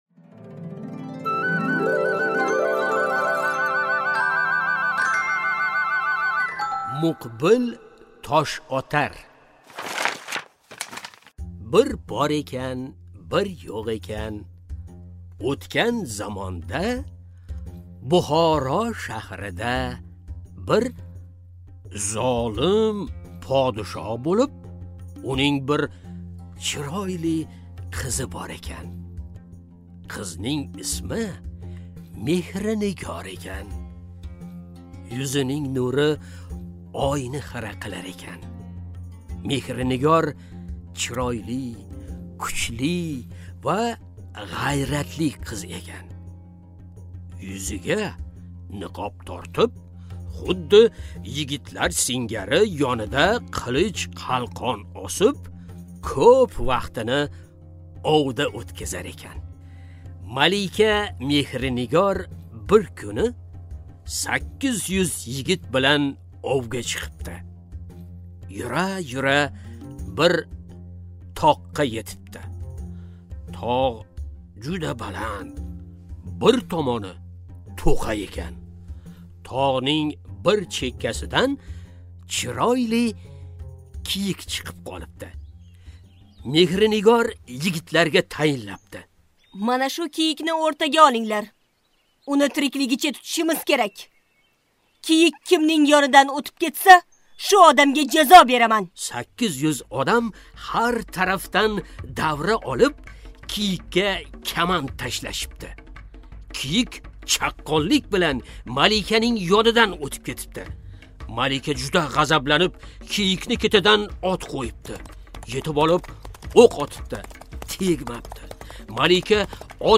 Аудиокнига Muqbil toshotar